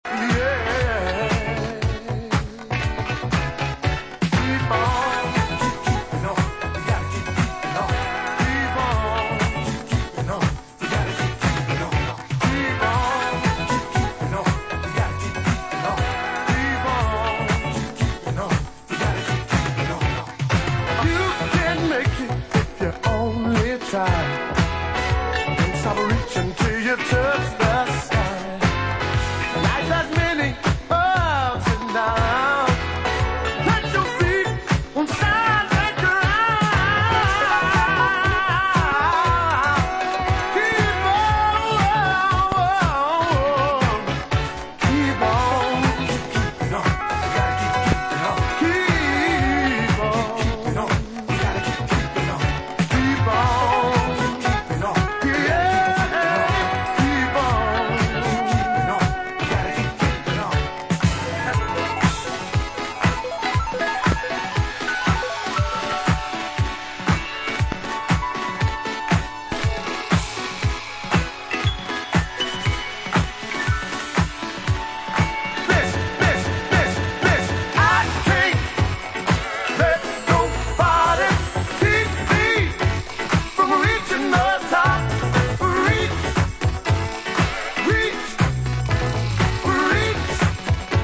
2 classic 80's bangers